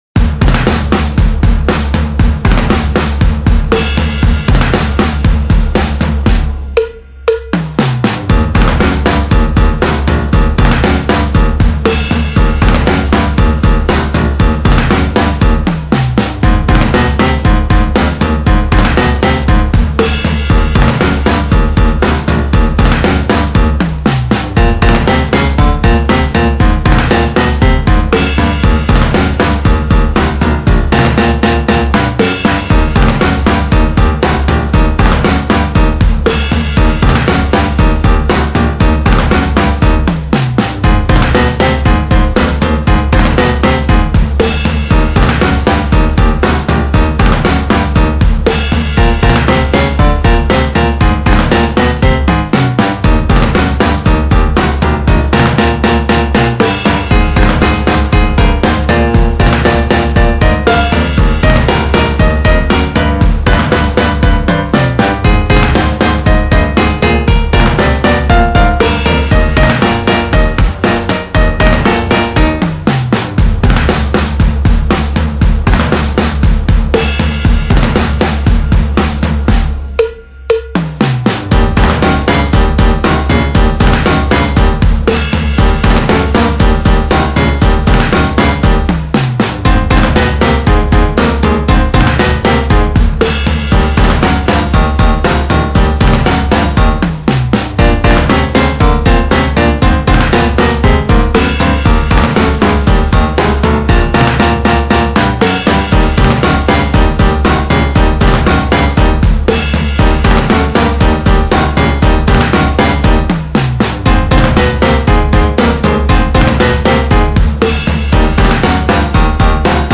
【コミカル系BGM11】
【用途/イメージ】　アニメ　ナレーション　動物　etc